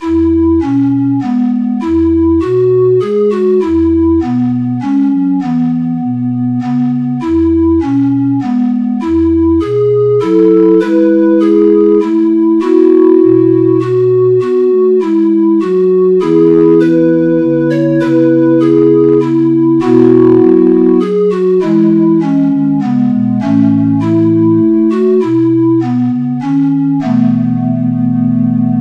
Midi File, Lyrics and Information to Hard is the Fortune